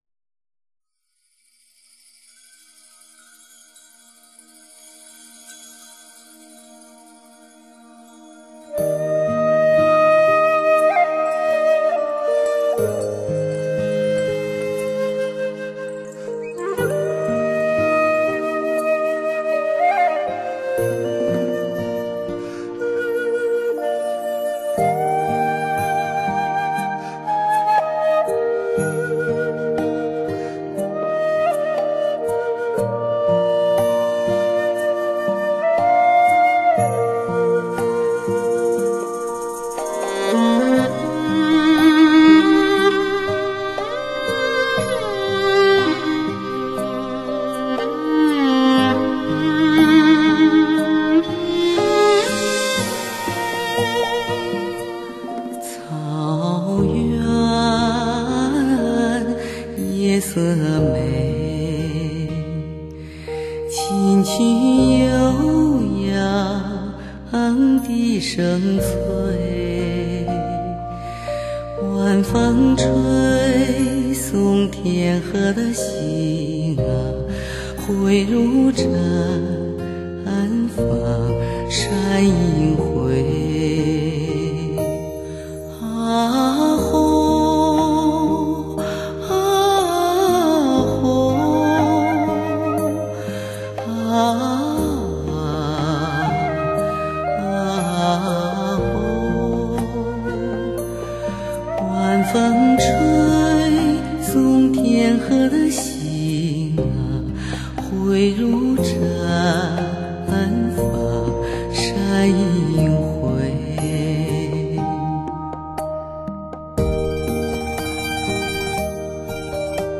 她的歌声因曲折而美丽  因迂回而感动
无与伦比的歌声与震撼空灵的音乐融为一体
（试听为低品质wma格式，下载为320k/mp3）